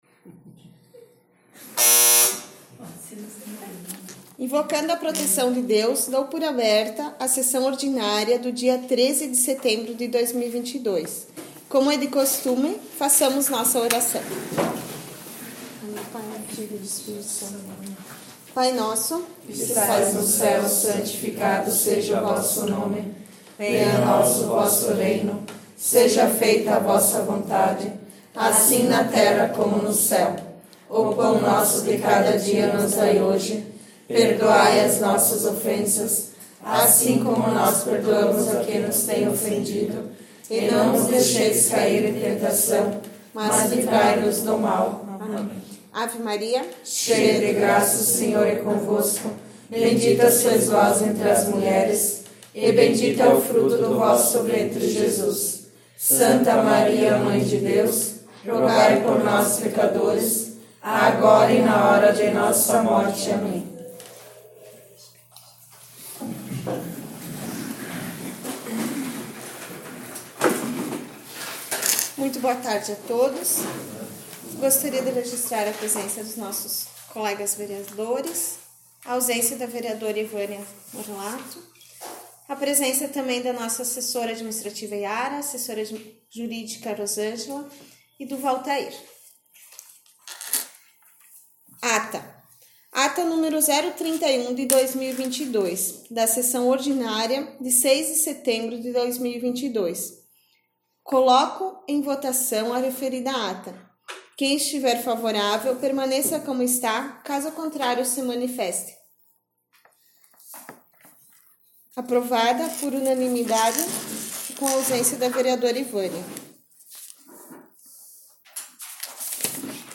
27 - Sessão Ordinária 13 de set 18.01.mp3